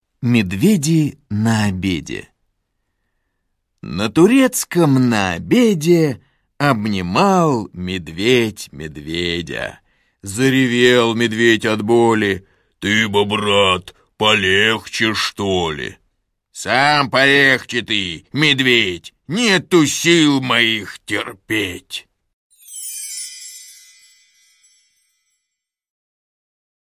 Аудио стих «Медведи на обеде»